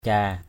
/ca:/ (d.) vương huy bắt chéo ngang ngực.